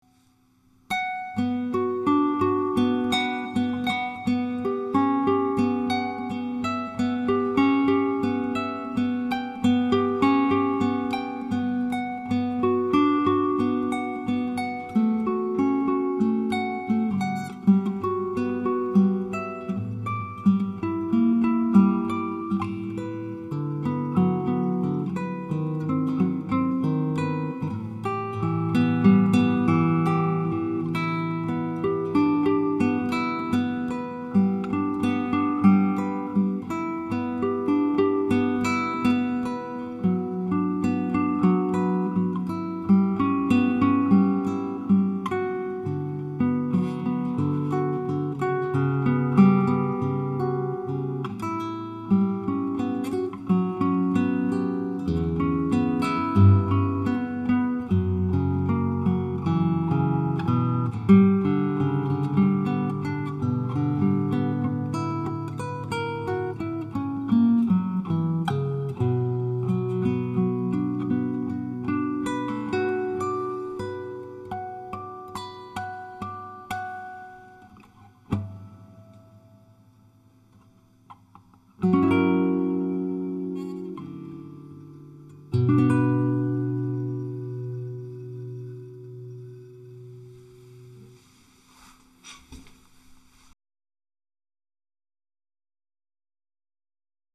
Construction de ma Guitare Classique.